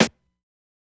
SNARE_NOCHO.wav